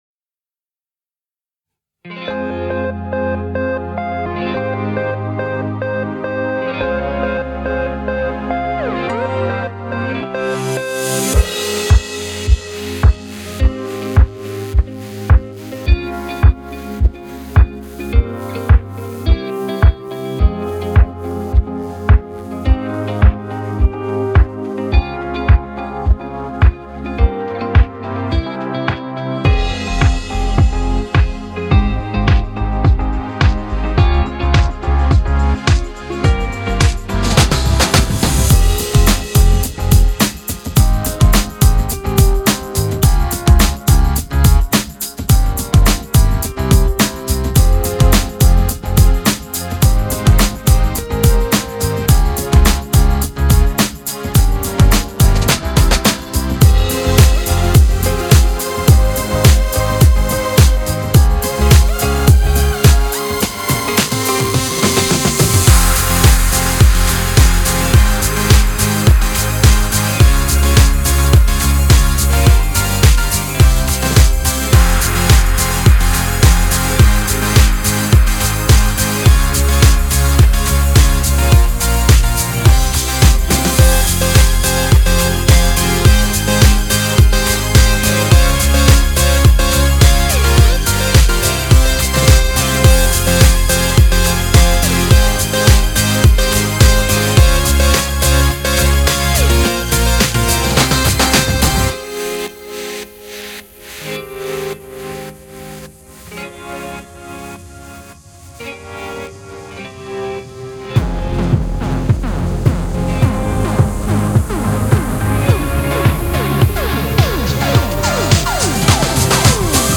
Жанр: поп, данс-поп, евродэнс ?